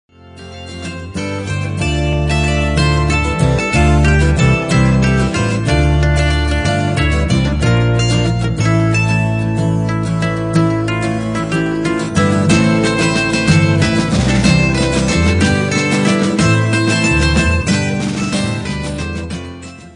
Celtic Rock